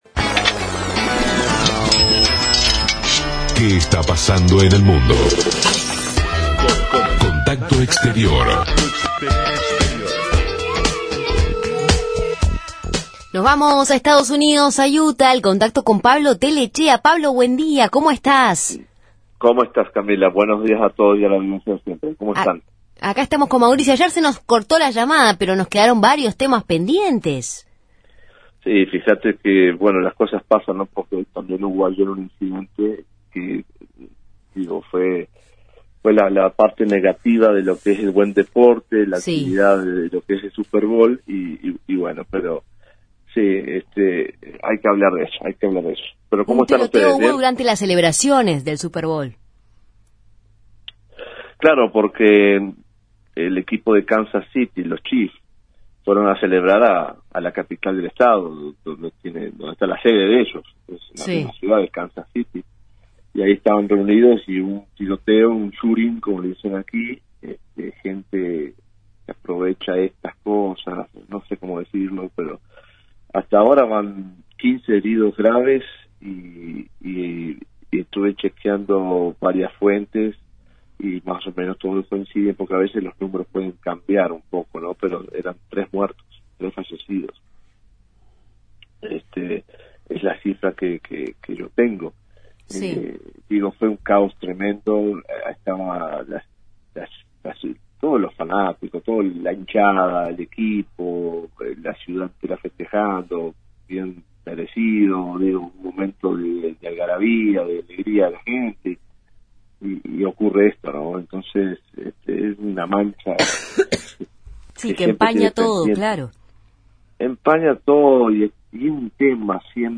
Corresponsal
CORRESPONSAL-EEUU.mp3